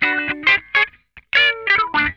CRUNCHWAH 8.wav